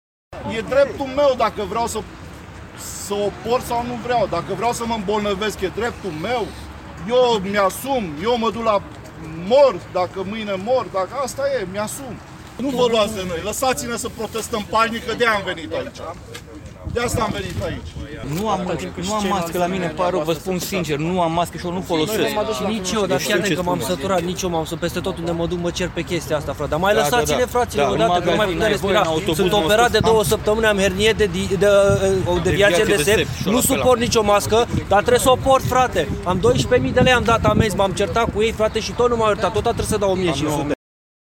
INSERT-VOXURI-PROTESTE-LUNI.mp3